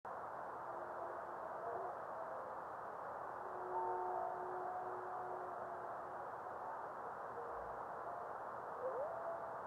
Observer's Comments:  Just a quick but intersting radio reflection.
Meteor occurs during 1042-1043 UT minutes. Only a minimal reflection.